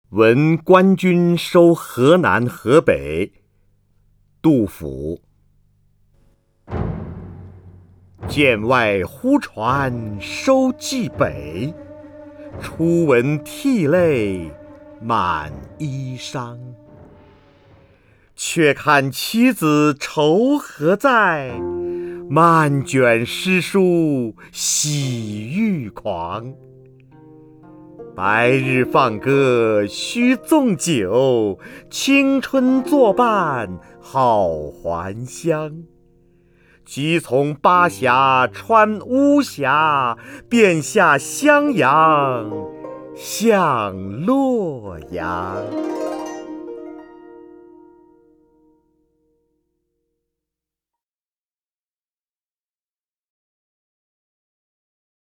首页 视听 名家朗诵欣赏 瞿弦和
瞿弦和朗诵：《闻官军收河南河北》(（唐）杜甫)　/ （唐）杜甫
WenGuanJunShouHeNanHeBei_DuFu(QuXianHe).mp3